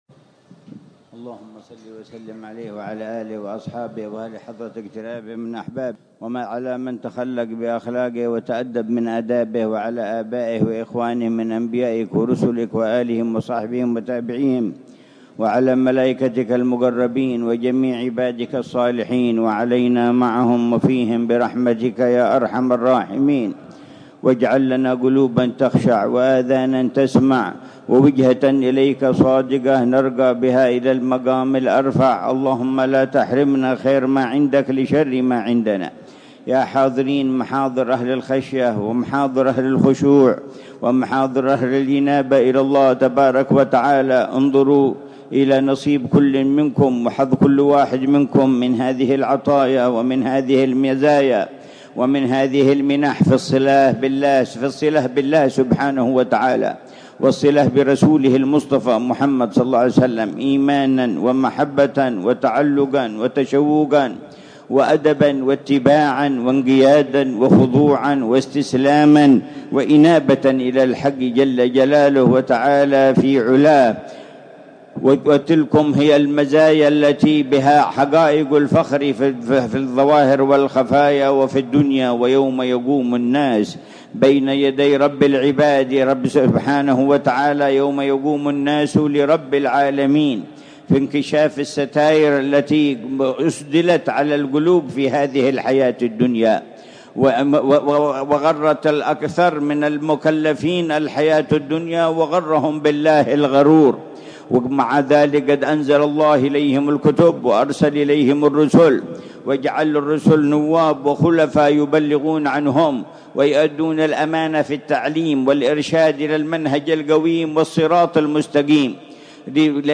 مذاكرة الحبيب عمر بن حفيظ في المولد السنوي في زاوية الشيخ سالم بن فضل بافضل، بمدينة تريم، حضرموت، ضحى الثلاثاء 24 ربيع الأول 1447هـ